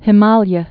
(hĭ-mälyə)